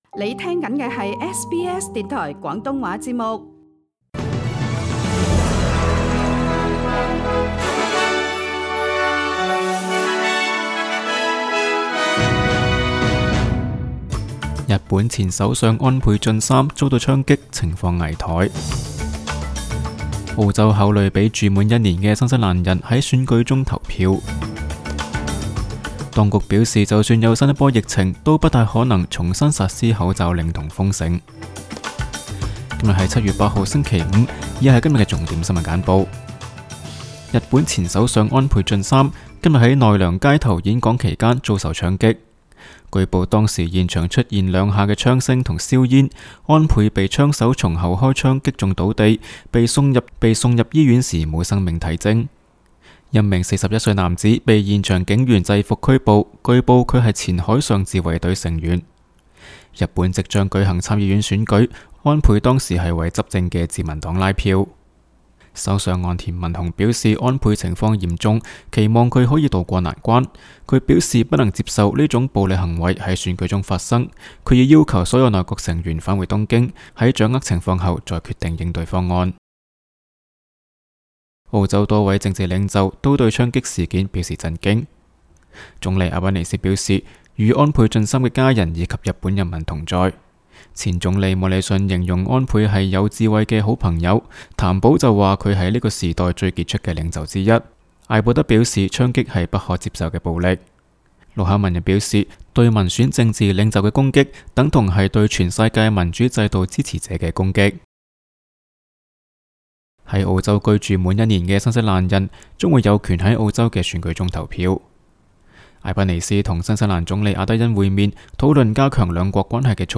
SBS 新闻简报（7月8日）